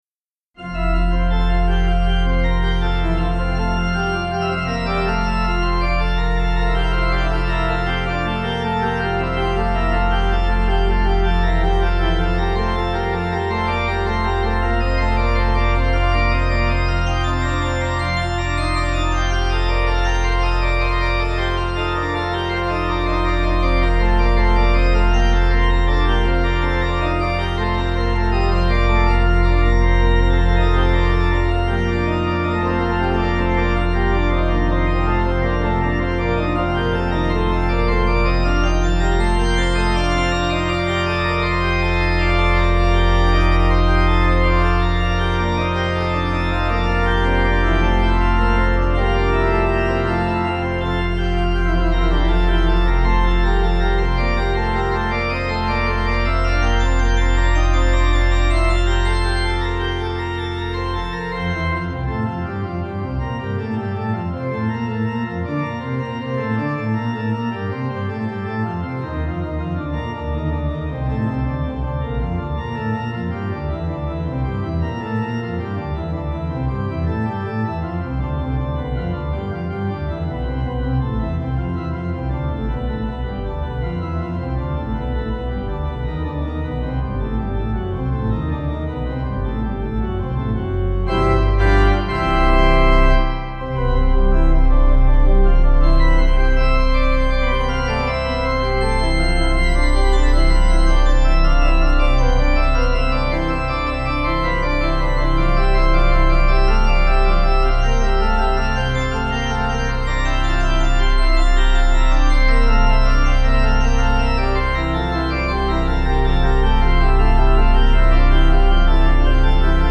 Organ
Easy Listening   F 186.9kb